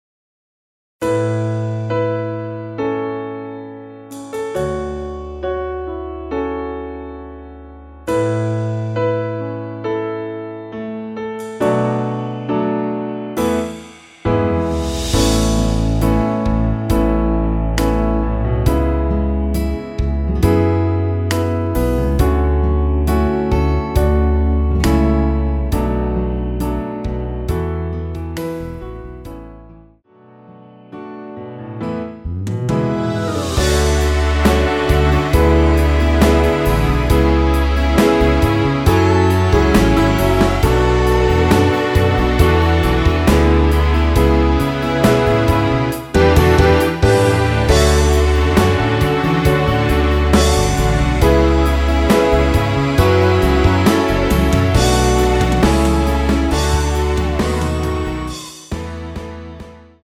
짧은버젼 MR 입니다.
원키에서(+3)올린 2절 부터 시작 하게 편곡 하였습니다.(미리듣기 참조)
앞부분30초, 뒷부분30초씩 편집해서 올려 드리고 있습니다.
중간에 음이 끈어지고 다시 나오는 이유는